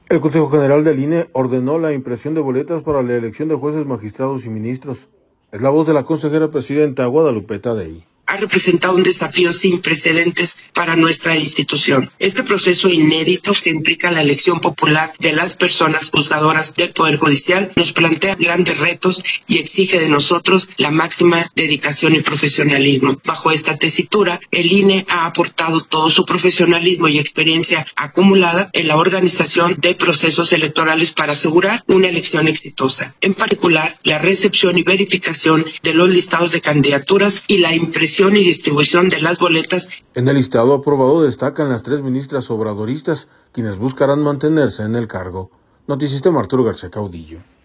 El Consejo General del INE ordenó la impresión de boletas para la elección de jueces, magistrados y ministros. Es la voz de la consejera presidenta, Guadalupe Taddei.